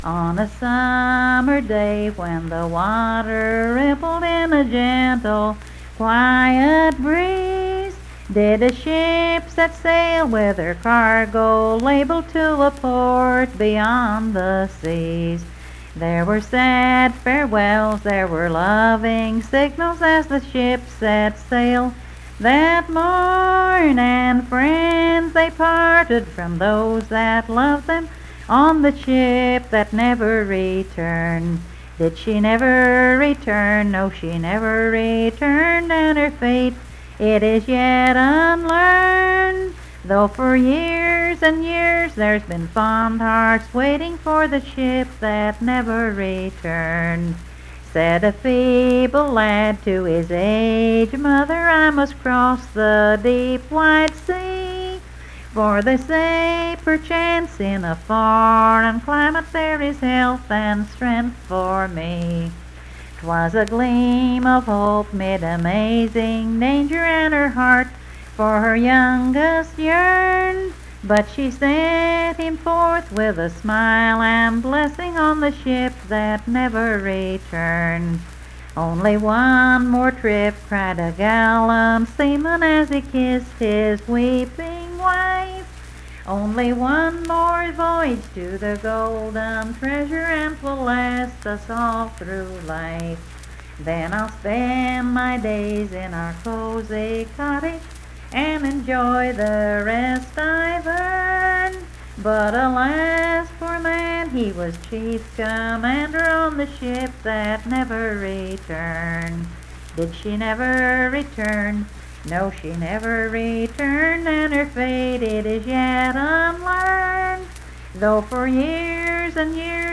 Unaccompanied vocal music
Verse-refrain 6(4)&R(4).
Performed in Coalfax, Marion County, WV.
Voice (sung)